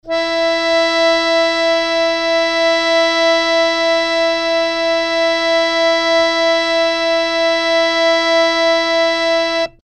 harmonium
E4.mp3